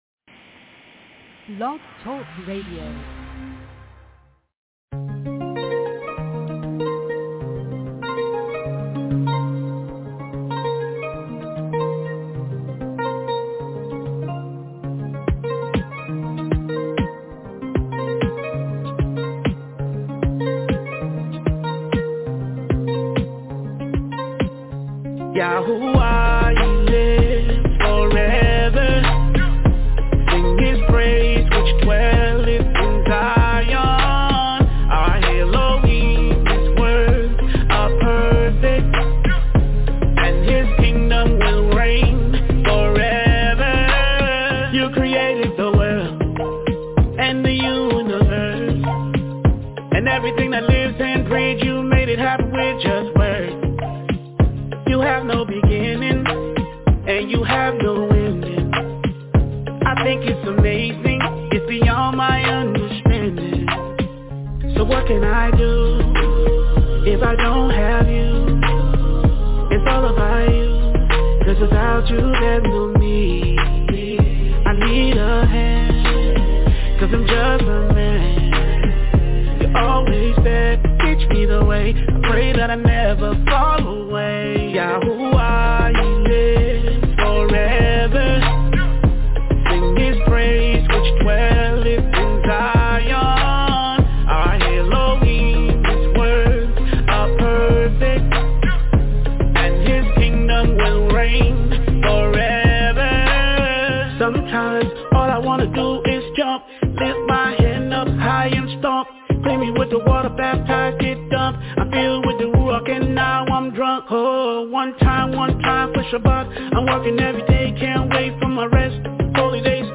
Friday Evening Sabbath Lesson